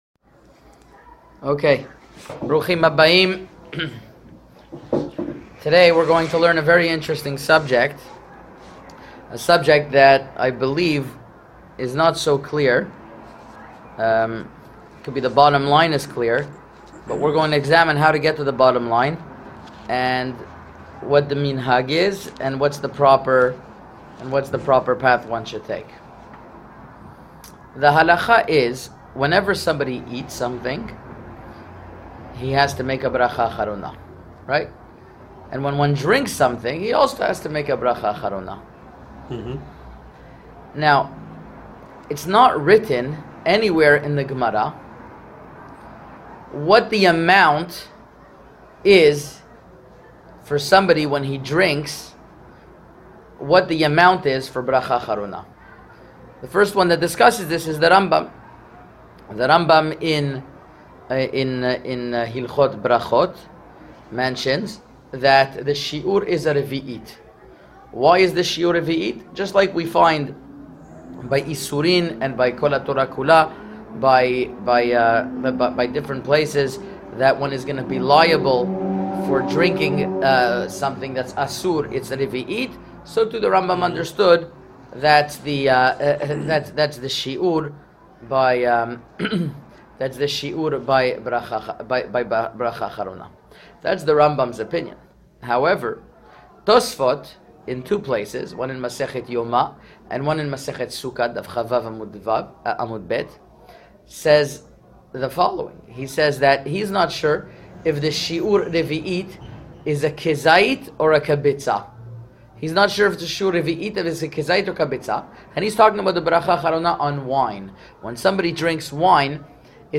An Torah audio Shiur